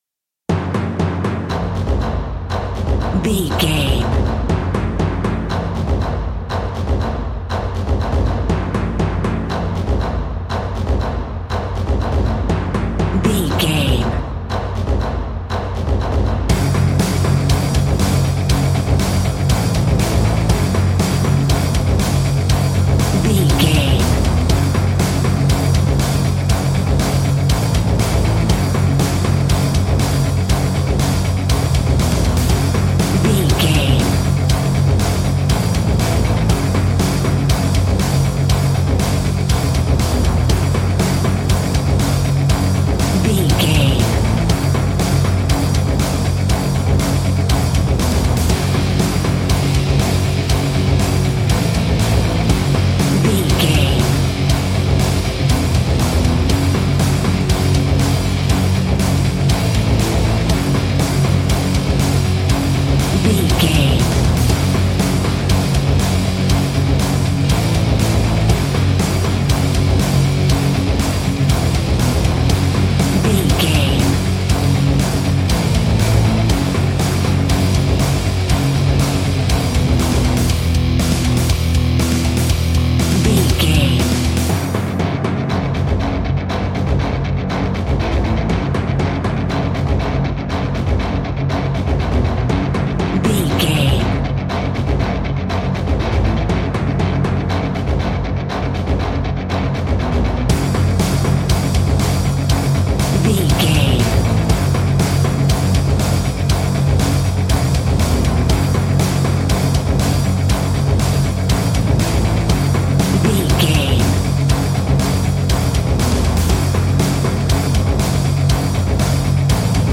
Epic / Action
Fast paced
Aeolian/Minor
F#
hard rock
heavy metal
scary rock
rock instrumentals
Heavy Metal Guitars
Metal Drums
Heavy Bass Guitars